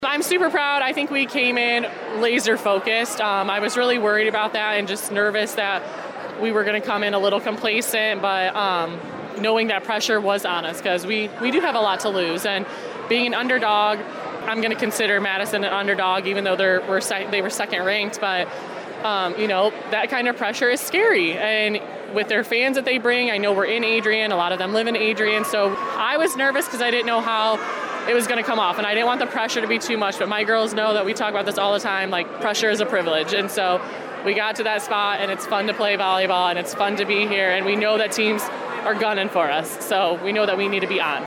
tec-volly-district-title-11-6-25.mp3